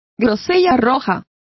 Complete with pronunciation of the translation of redcurrant.